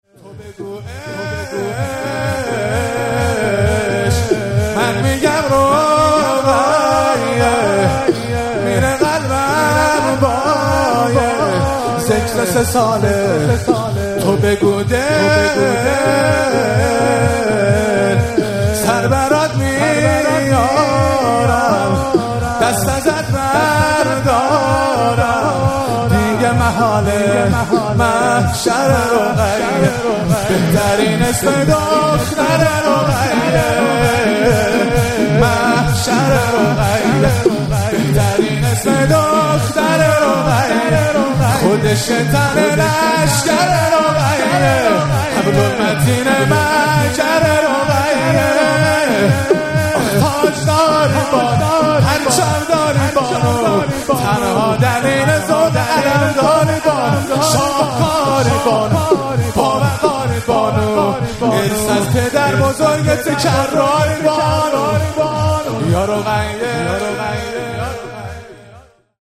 مراسم مناجات خوانی و احیای شب بیست و یکم و عزاداری شهادت حضرت امیرالمومنین علی علیه السلام ماه رمضان 1444